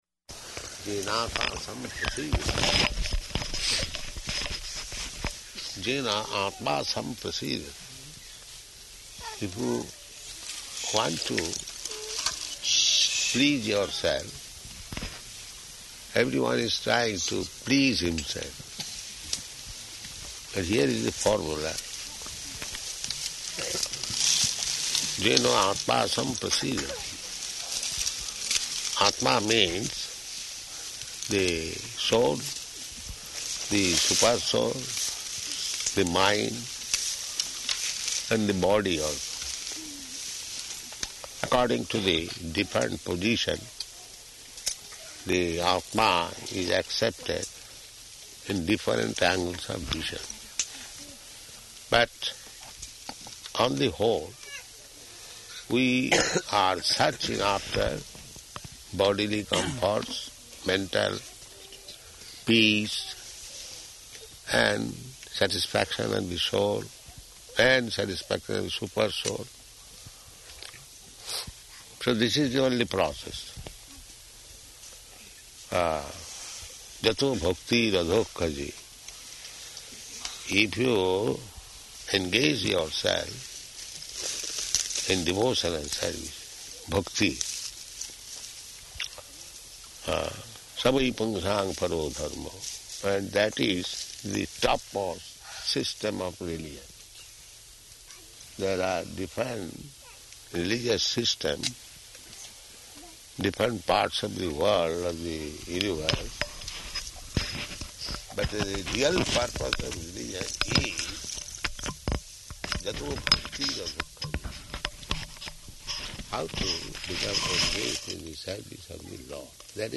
Lecture During Walk [partially recorded]
Location: New Māyāpur